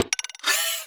Robot Bulletin Notification.wav